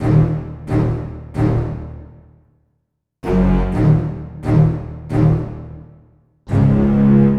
Bass 34.wav